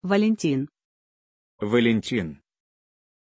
Pronunciation of Vallentin
pronunciation-vallentin-ru.mp3